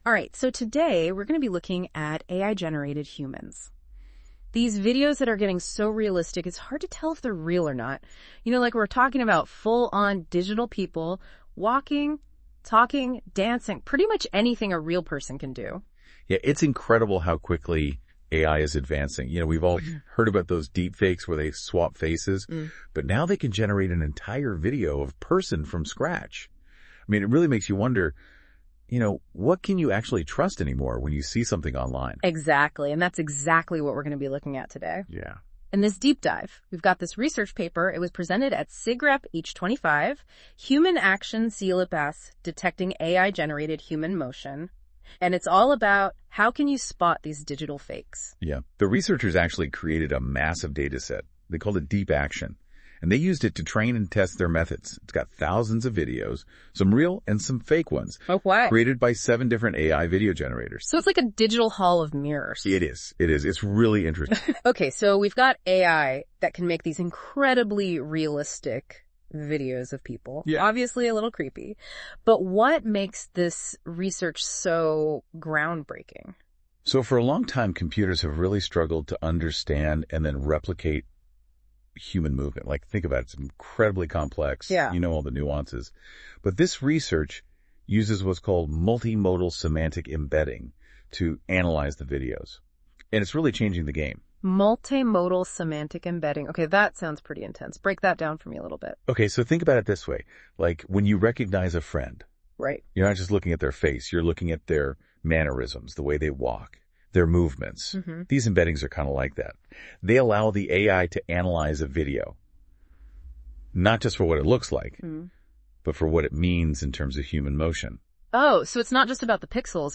Human Action CLIPs: Detecting AI-Generated Human Motion, IJCAI Workshop on Deepfake Detection, Localization, and Interpretability , 2025. [ paper ] [ AI-generated podcast ] H. Farid.